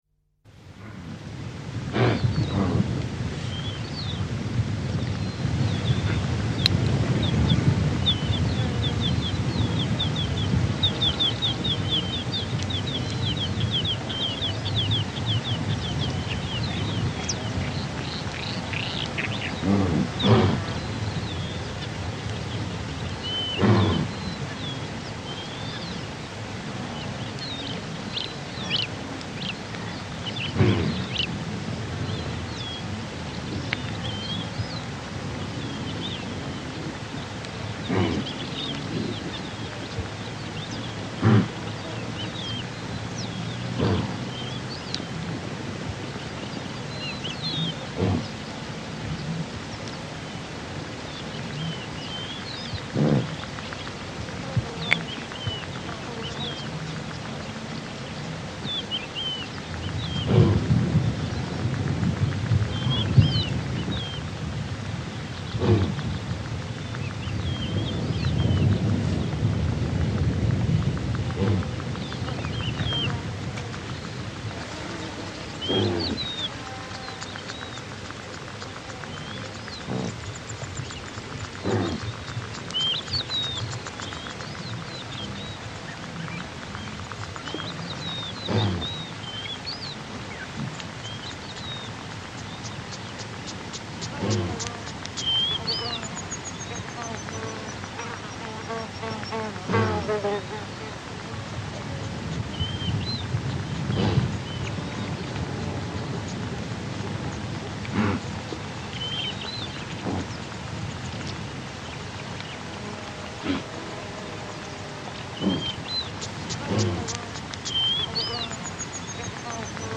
Шум саванны, где в летней траве слышны гну и пара газелей